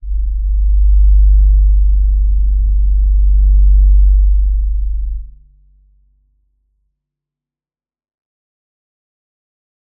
G_Crystal-G1-mf.wav